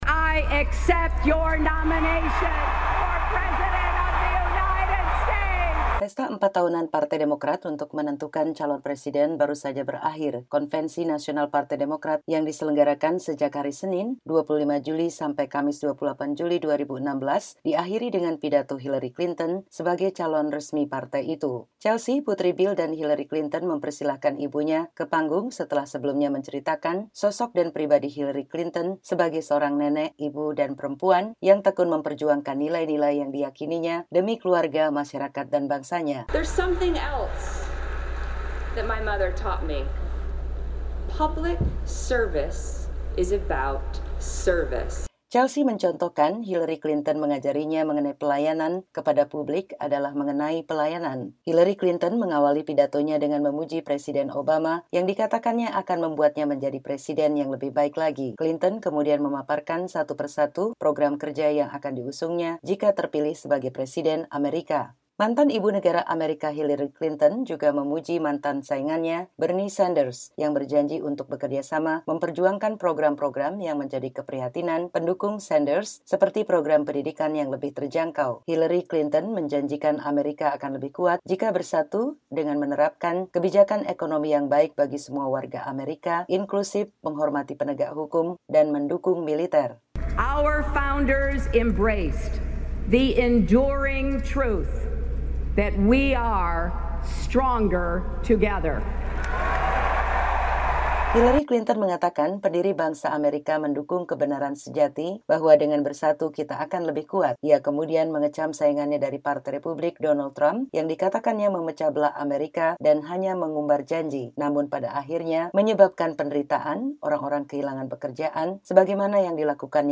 Hillary Clinton telah menyampaikan pidato pertamanya sebagai calon presiden AS di hadapan Konvensi Nasional Partai Demokrat, dan menerima secara resmi pencalonan partainya.
Pidato Hillary Clinton dalam Konvensi Nasional Partai Demokrat